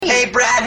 autotune